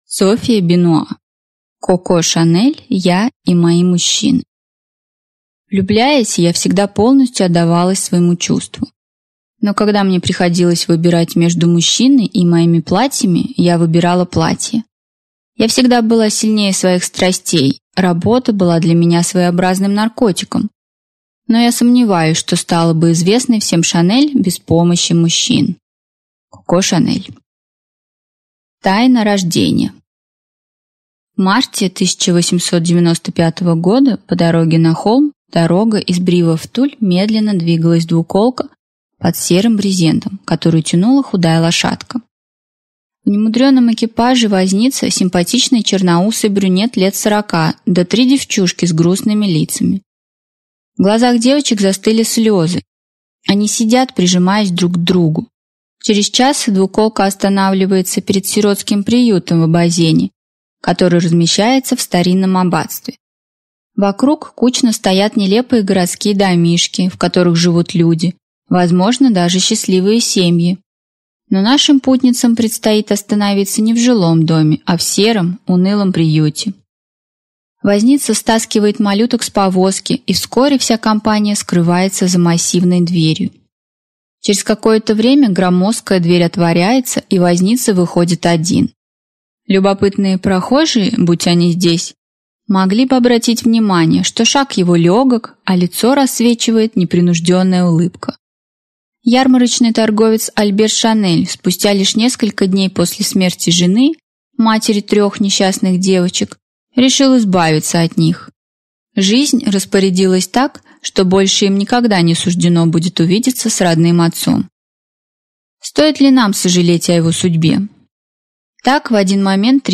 Аудиокнига Коко Шанель. Я и мои мужчины | Библиотека аудиокниг